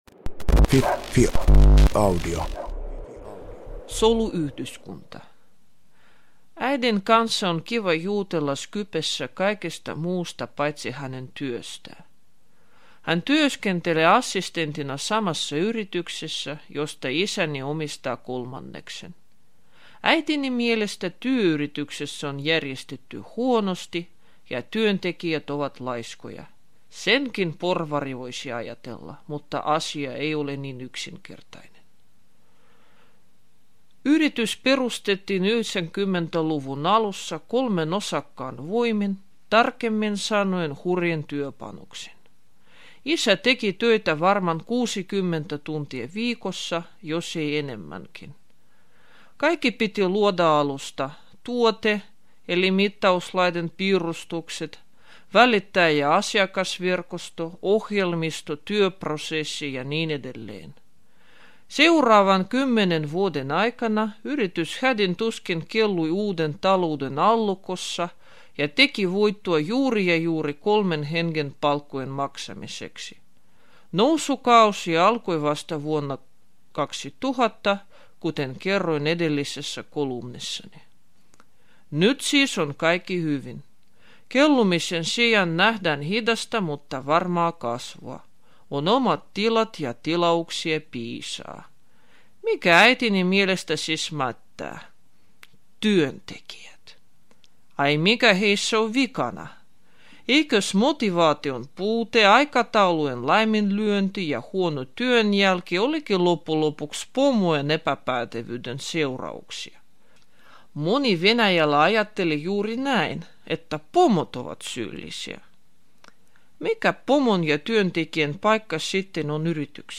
Audiokolumni